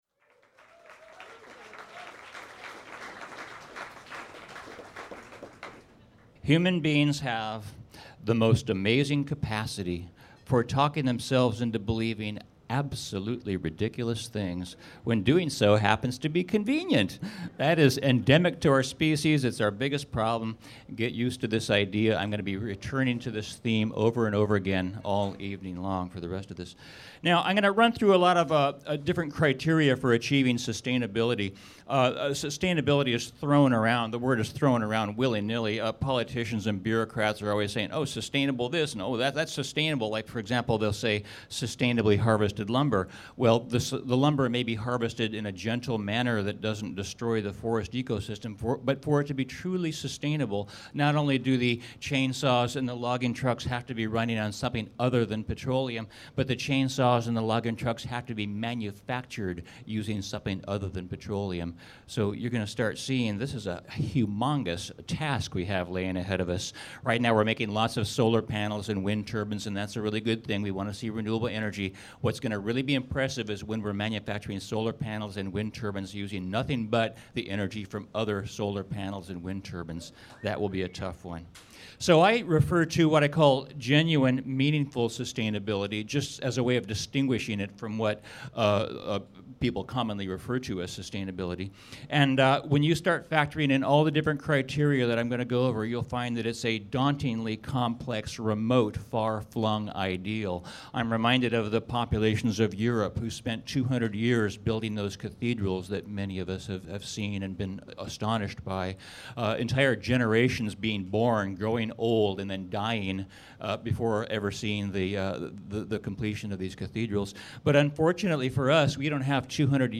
2009 Village Building Convergence